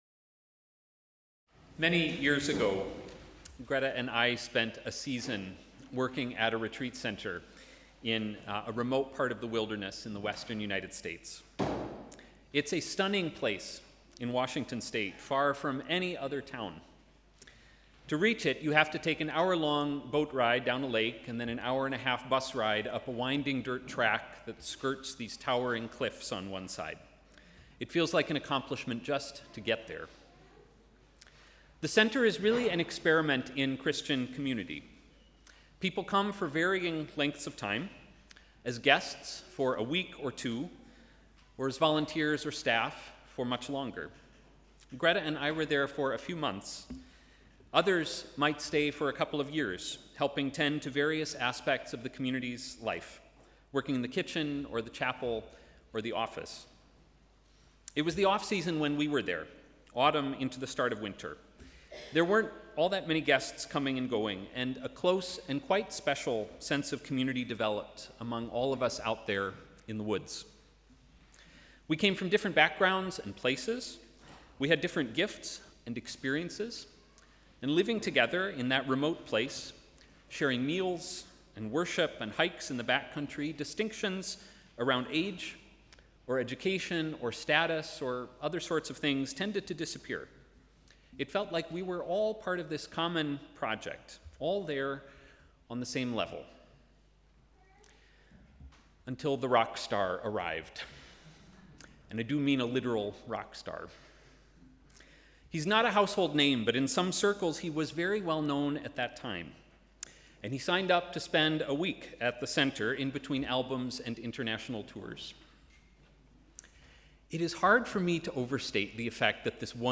Sermon: ‘A gracious invitation’